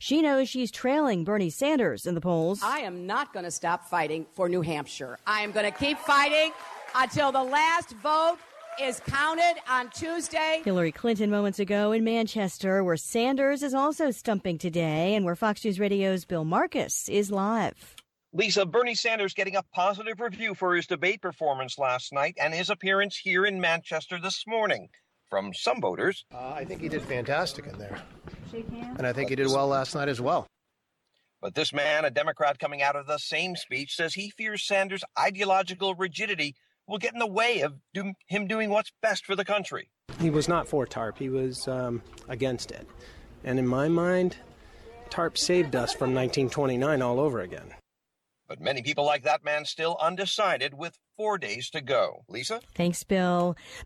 (MANCHESTER, NH) FEB 5 – 2PM LIVE –